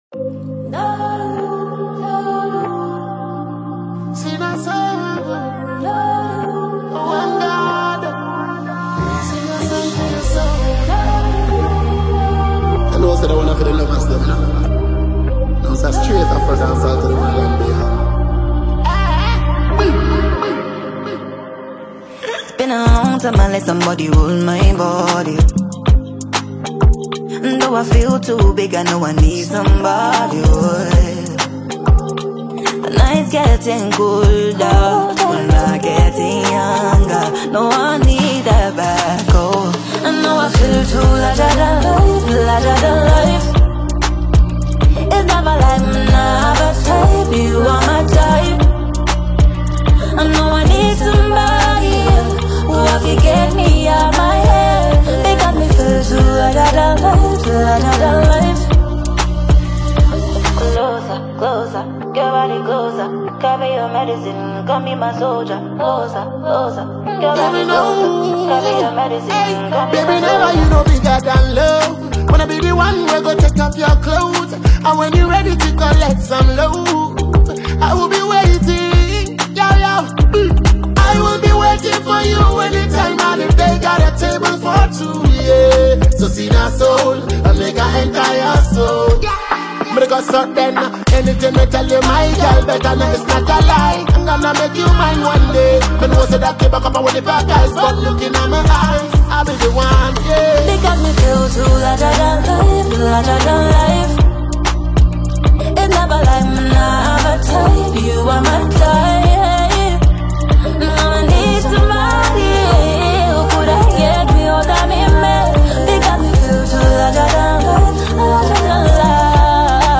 Ghanaian Songstress